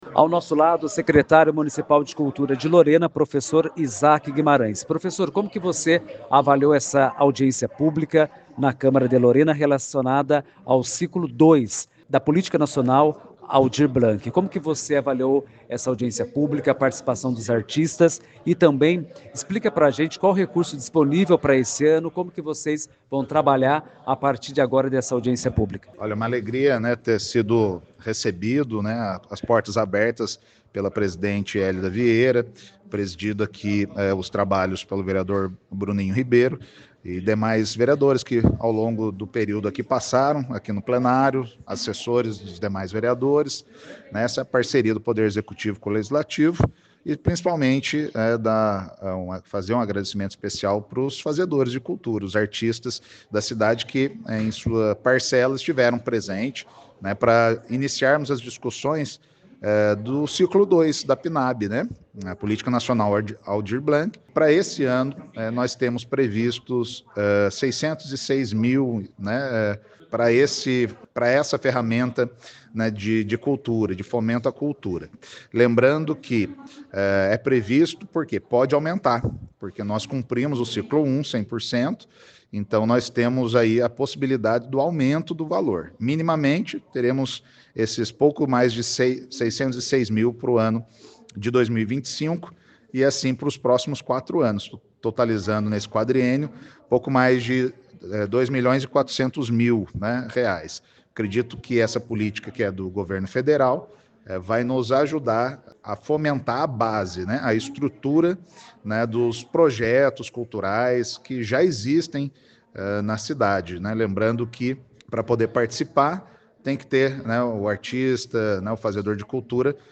Câmara de Lorena recebe audiência pública sobre o ciclo 2 da PNAB
Encontro, que aconteceu no plenário na manhã desta quinta-feira (12), também recebeu sugestões de fazedores de cultura local.
Isaque Guimarães, secretário municipal de Cultura.